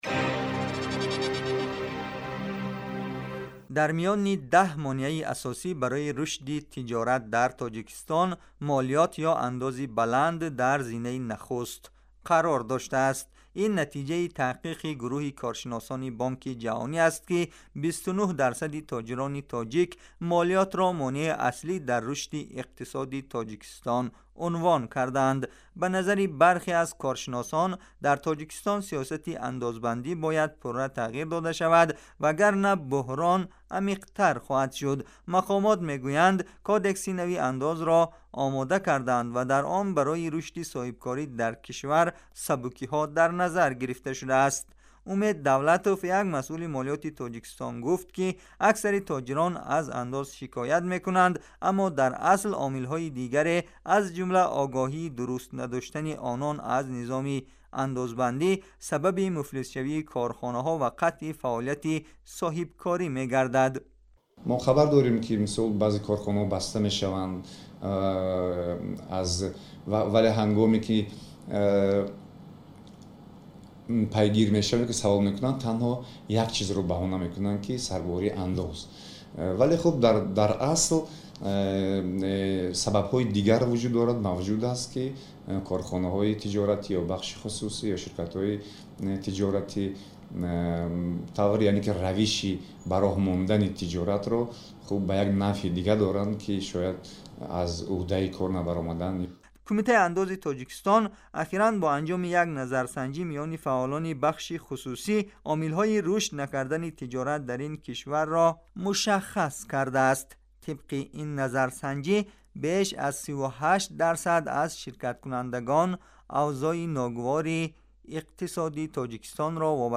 Гузориши вижа : Системаи молиётбандии Тоҷикистон бознигарӣ мехоҳад